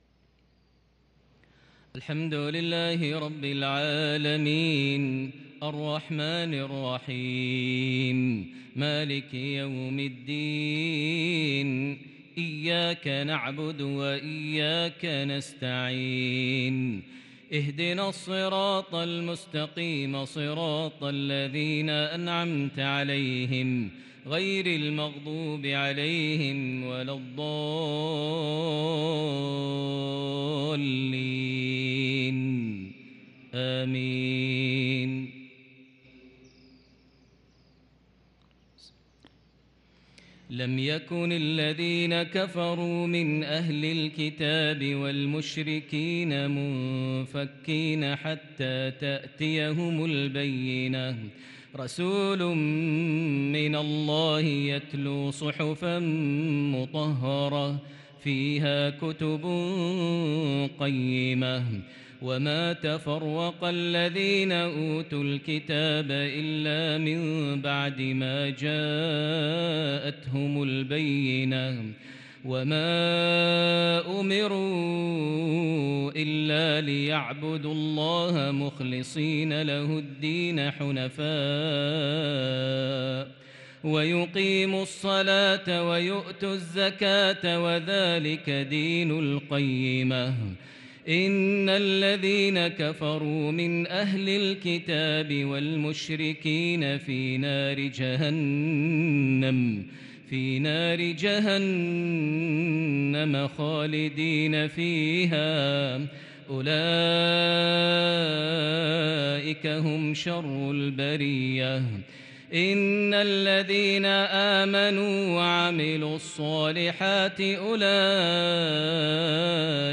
صلاة المغرب للقارئ ماهر المعيقلي 27 ربيع الأول 1443 هـ
تِلَاوَات الْحَرَمَيْن .